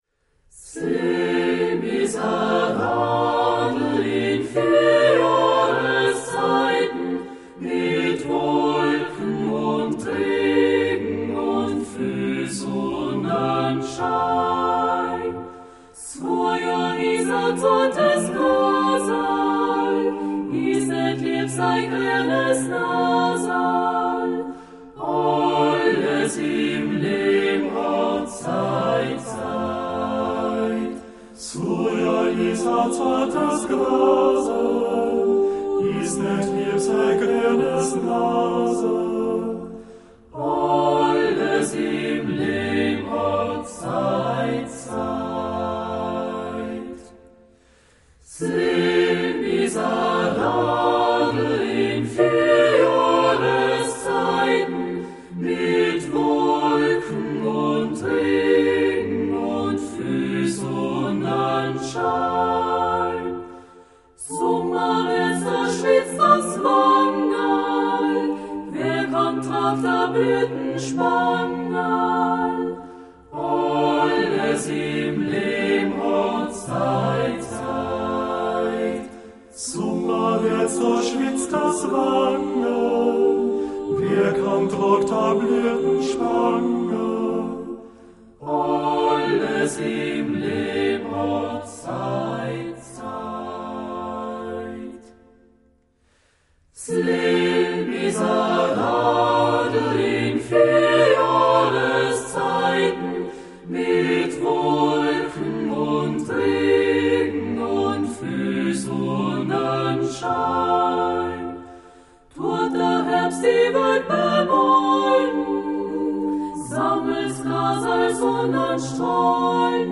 alpenländisches Chorlied